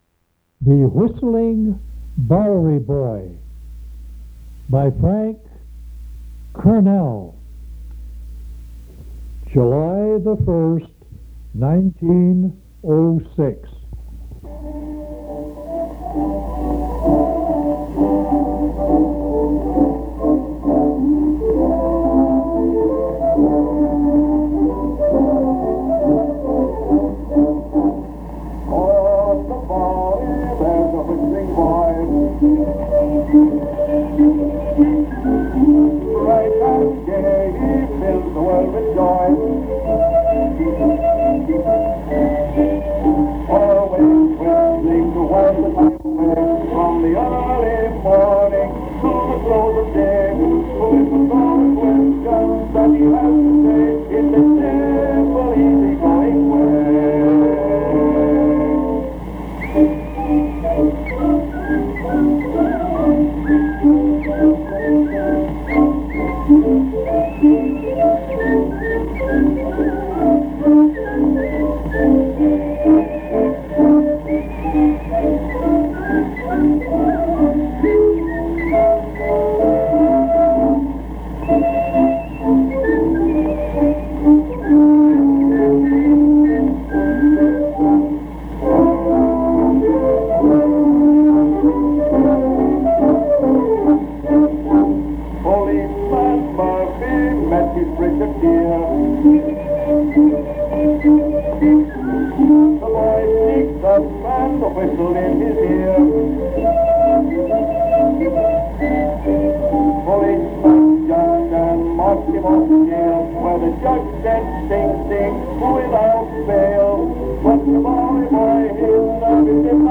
Popular music